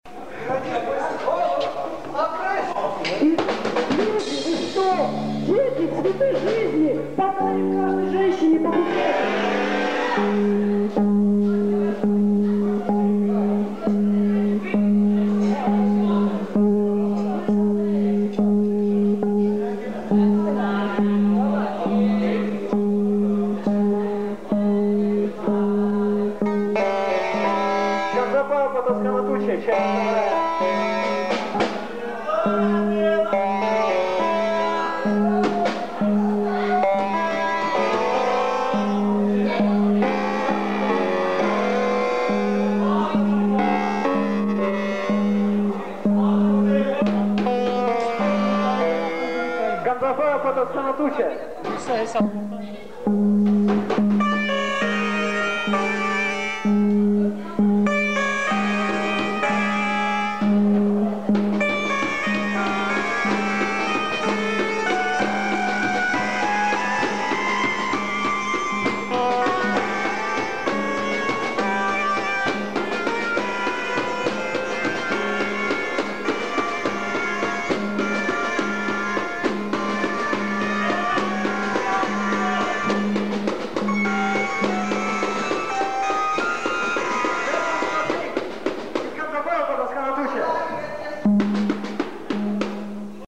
КОНЦЕРТ В КИНОТЕАТРЕ "ПИОНЕР"
синтезатор
барабанная установка